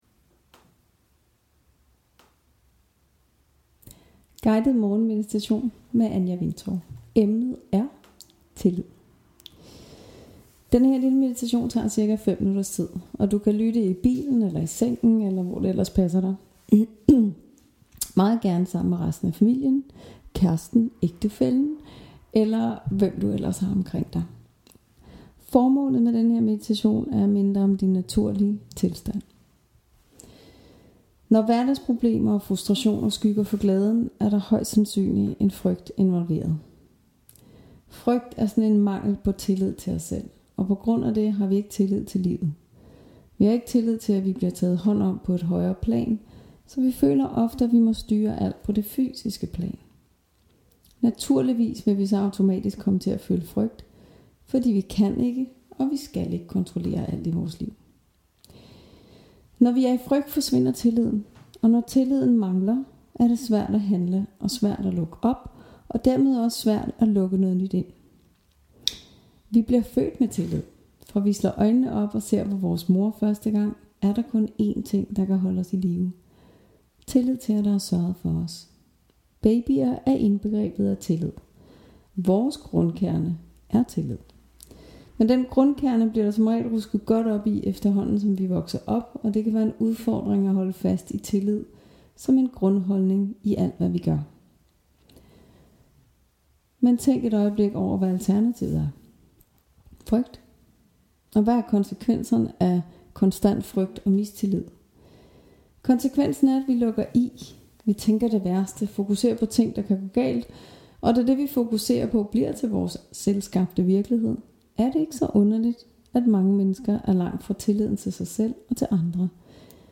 Guidet Morgen Meditation